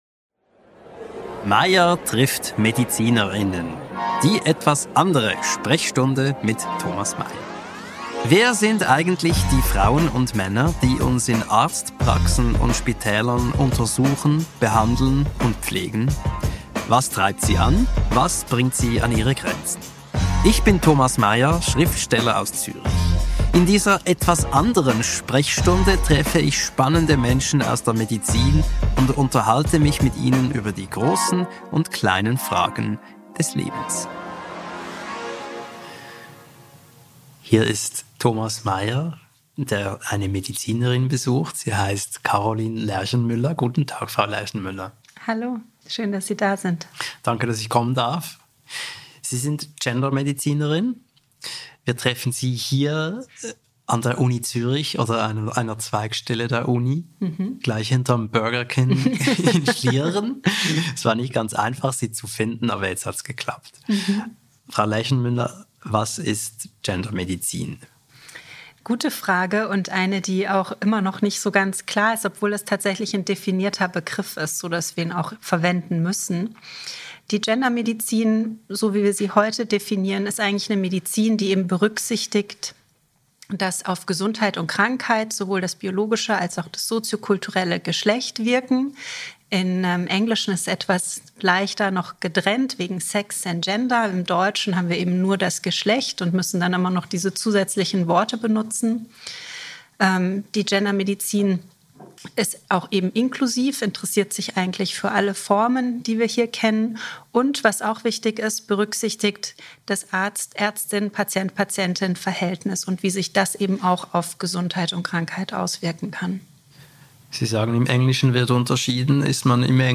Ironischerweise sind ihre und die Erkenntnisse ihrer Kolleginnen – es arbeiten hauptsächlich Frauen in diesem Fachgebiet – für beide «sexes» gleich lebenswichtig: Herzinfarkt, Depression, Hirnschlag und weitere Phänomene äussern sich je nach Geschlecht häufig unterschiedlich und werden oft nicht erkannt. Ein kurzweiliges Gespräch über Vorurteile, Fortschritt und die Herausforderung, ein positives Menschenbild zu wahren (es gelingt der Ärztin besser als dem Interviewer).